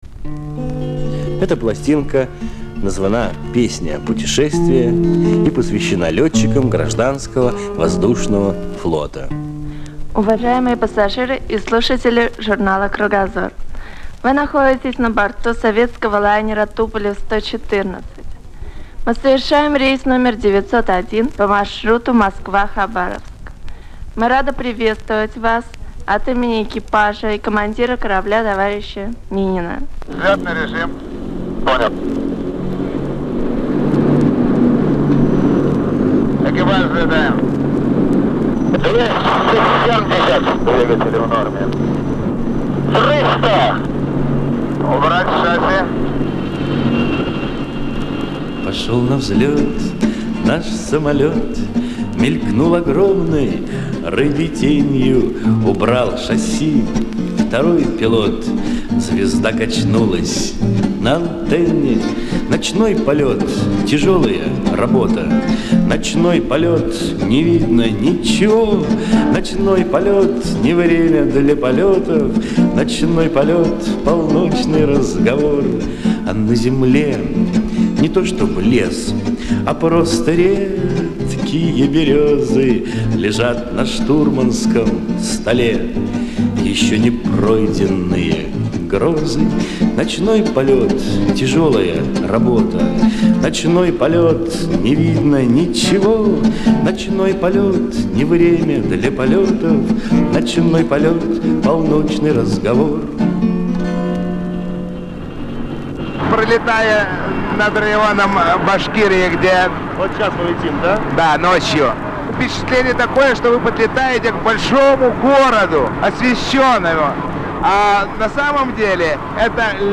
ПЕСНЯ-ПУТЕШЕСТВИЕ
Песня-путешествие Ю.Визбора.